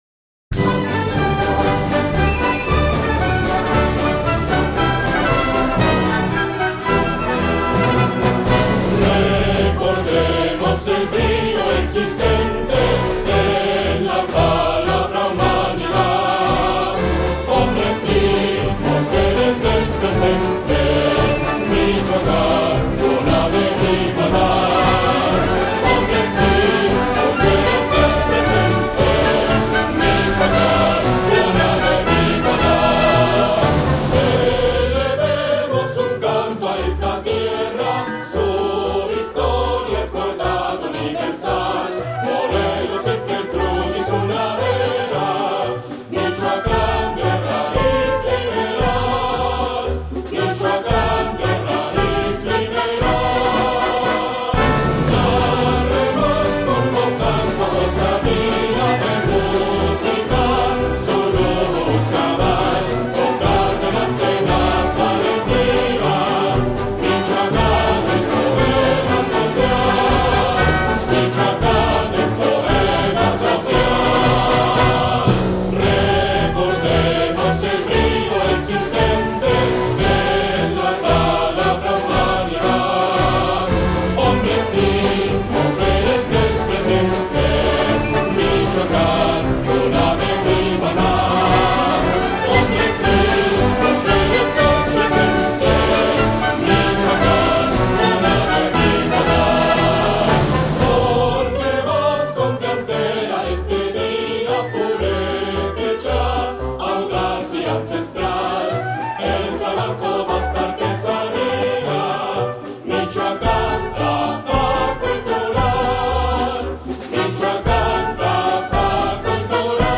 Himno completo (3:59)